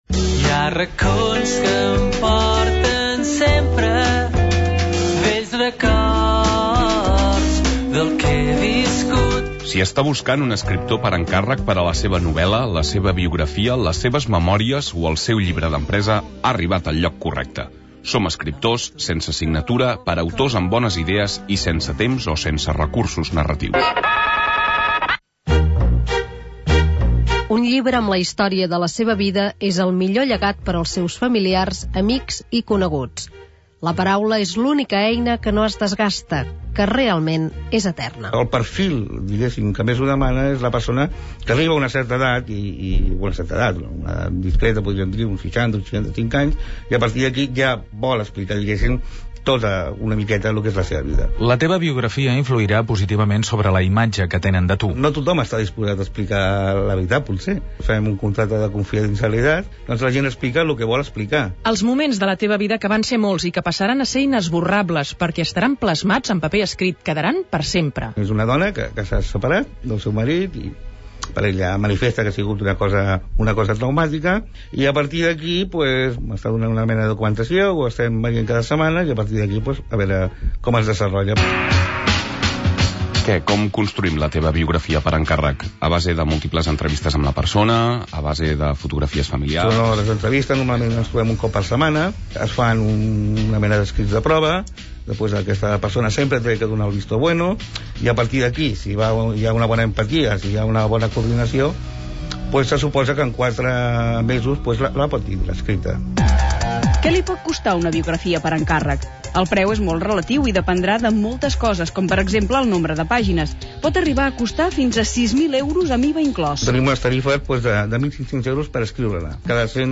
Reportatge "Una biografia per encàrrec"
Info-entreteniment